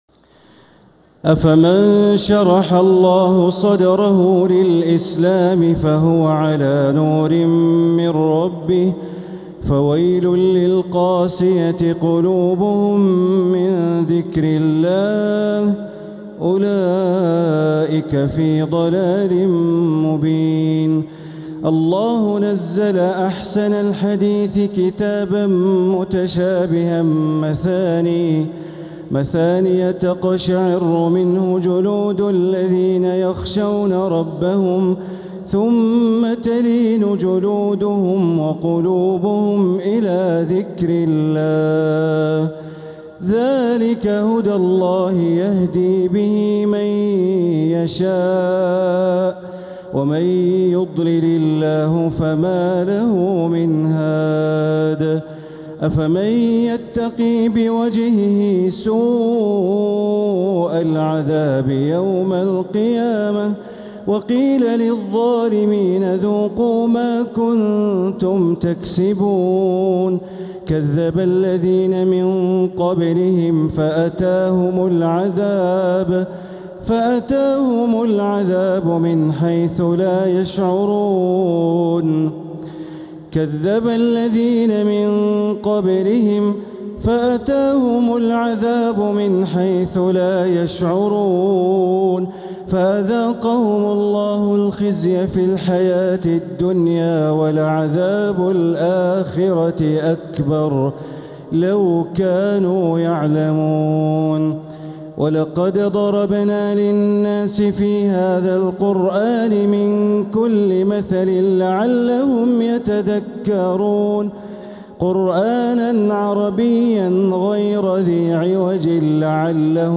تهجد ليلة 25 رمضان 1447هـ من سورتي الزمر (22) وغافر (1-58) > تراويح 1447هـ > التراويح - تلاوات بندر بليلة